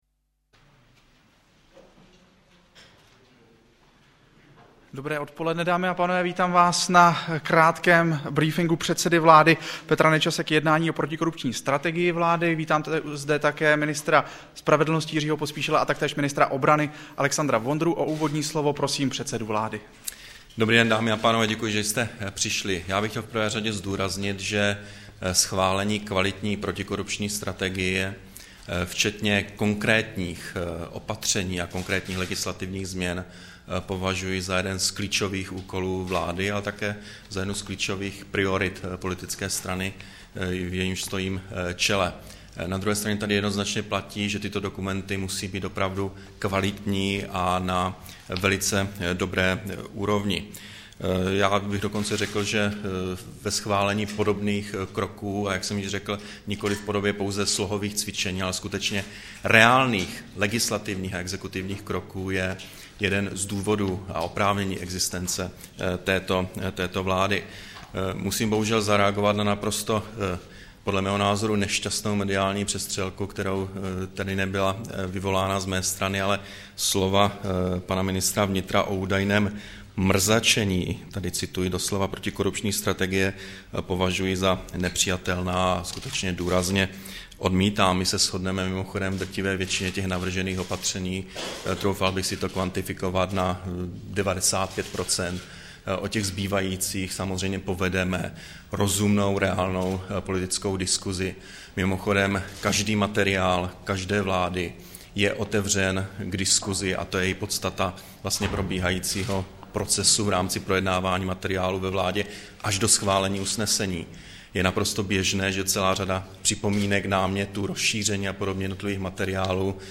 Brífink premiéra, ministra spravedlnosti a obrany k protikorupční strategii vlády, 4. ledna 2011